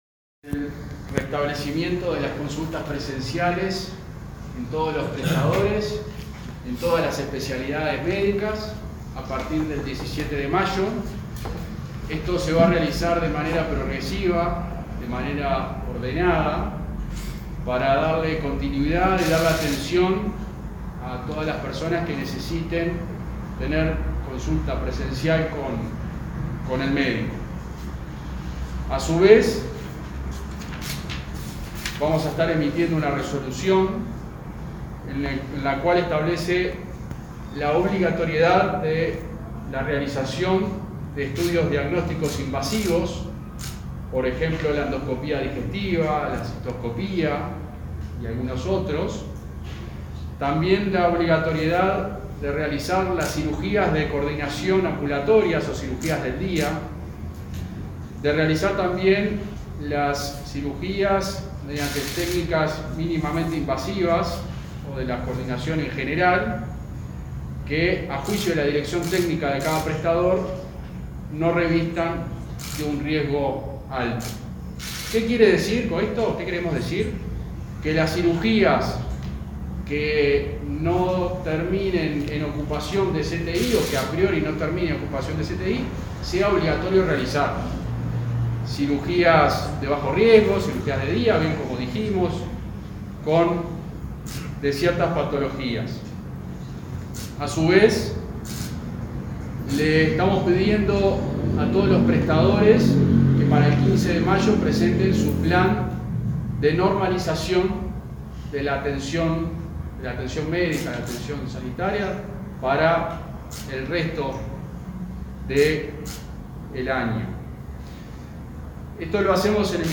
Conferencia de prensa de autoridades del Ministerio de Salud Pública
Conferencia de prensa de autoridades del Ministerio de Salud Pública 04/05/2021 Compartir Facebook X Copiar enlace WhatsApp LinkedIn Este martes 4, el subsecretario de Salud Pública, José Luis Satdjian, y el director general de Salud, Miguel Asqueta, brindaron una conferencia de prensa con motivo del retorno gradual de las consultas presenciales en los centros de salud.